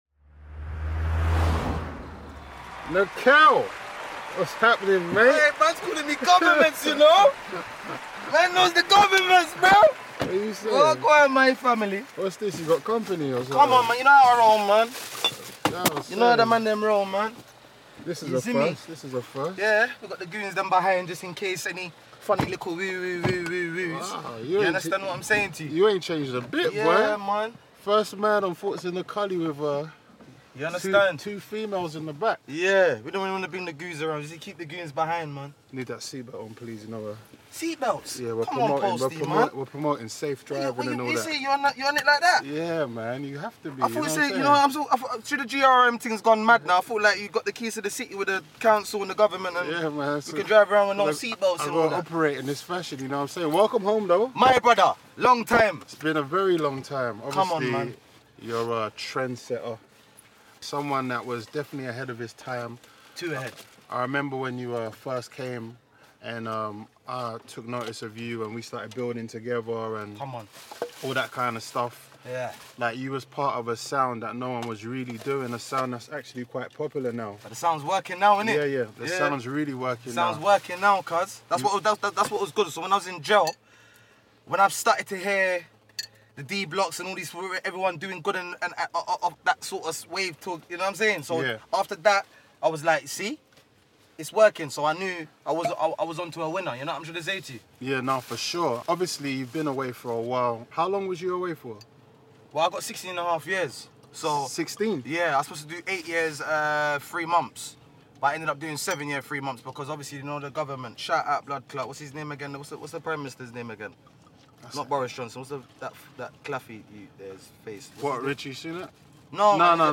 all while cruising in the iconic Rolls Royce. Thoughts In A Culli once again offers fans an intimate glimpse into the world of another figurehead of UK rap’s heritage.